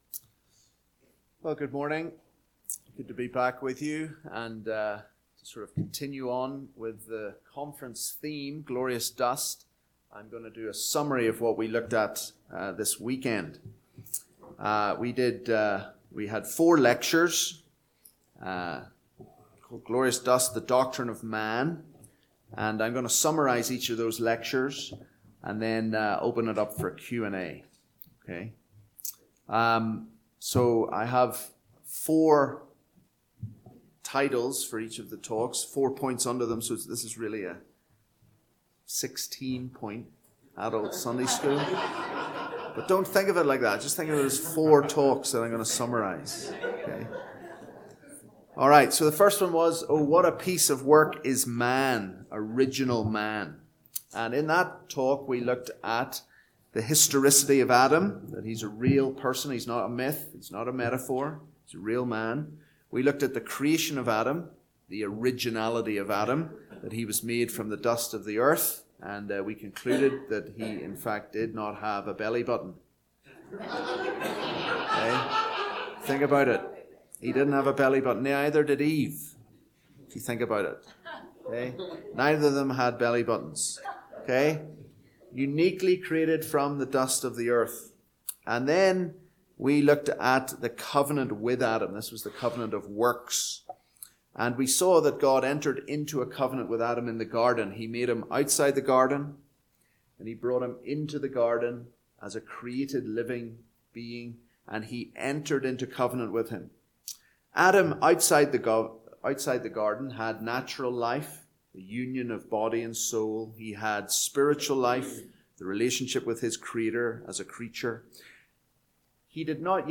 Session 5: Summary In this fifth session we do a re-cap of the whole conference and then have a time of question and answer.